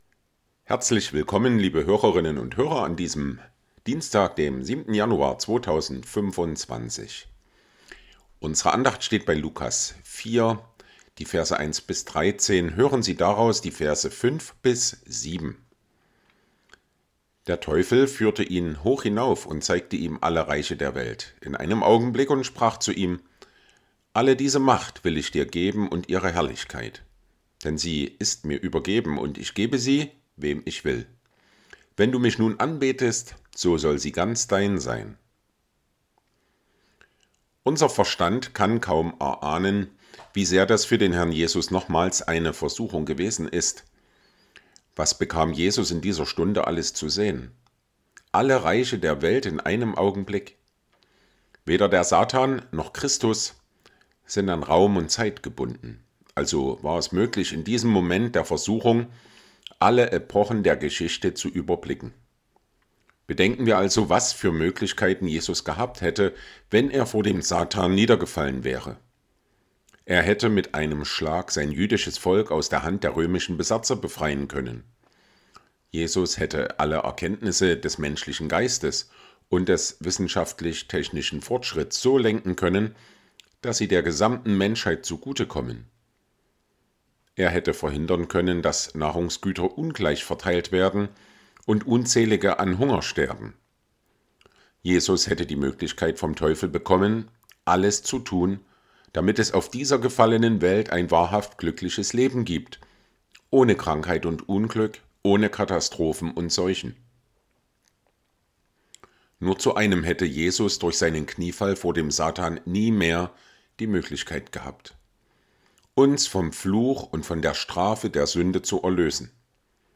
Andacht vom 07.01.2025
Tägliche Andachten aus dem Andachtsheft der Ev.-Luth. Freikirche